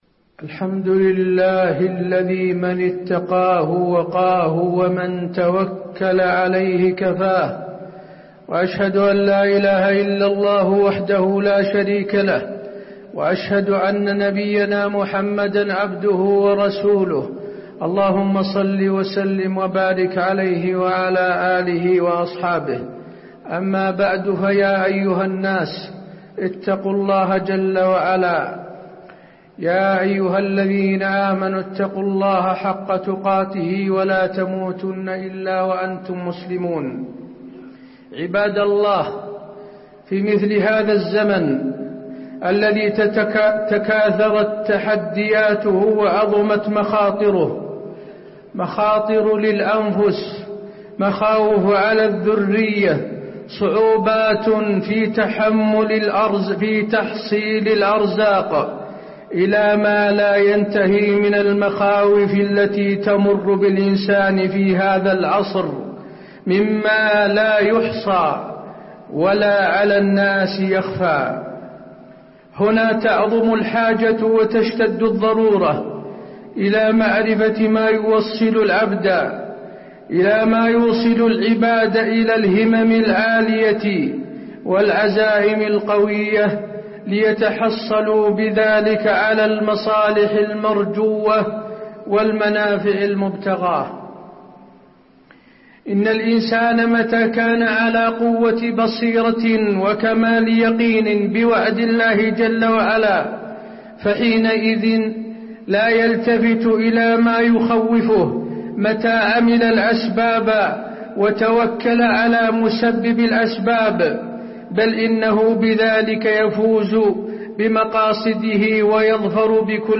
تاريخ النشر ٧ ذو القعدة ١٤٣٩ هـ المكان: المسجد النبوي الشيخ: فضيلة الشيخ د. حسين بن عبدالعزيز آل الشيخ فضيلة الشيخ د. حسين بن عبدالعزيز آل الشيخ فضل التوكل على الله تعالى